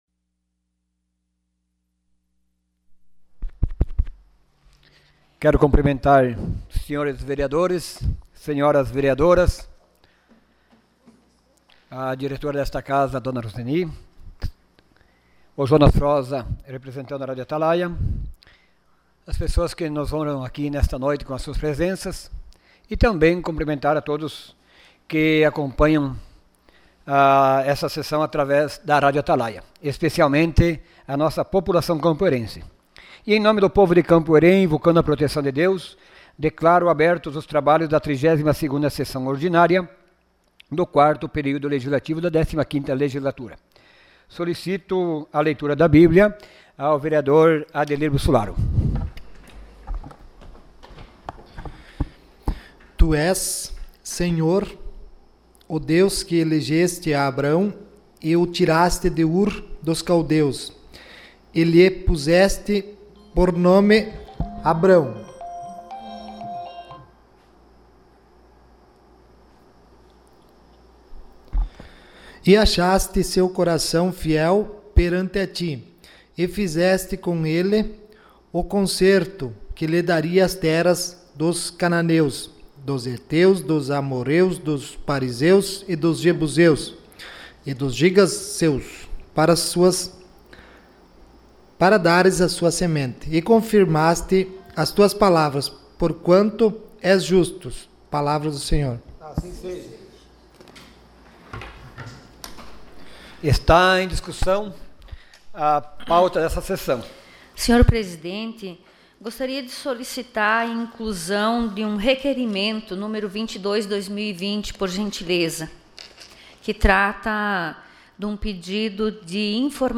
SESSÃO ORDINÁRIA DIA 19 DE OUTUBRO DE 2020